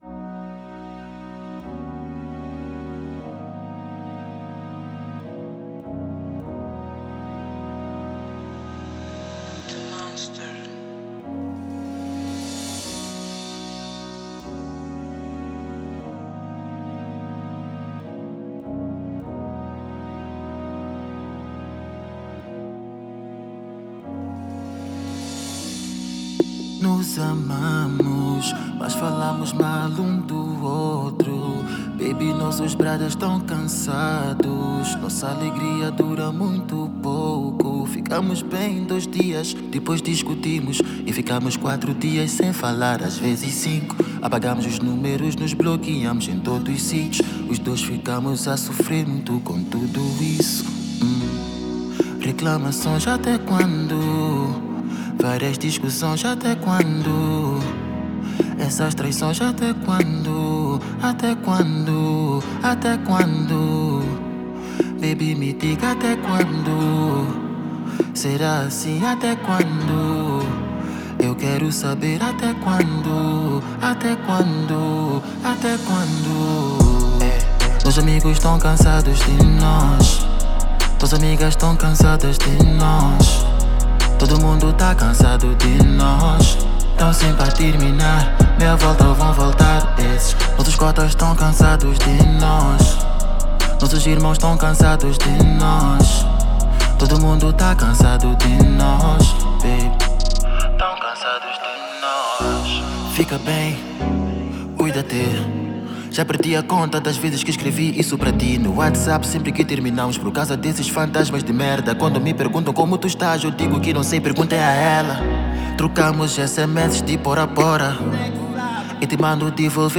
R&B
o mestre das rimas melódicas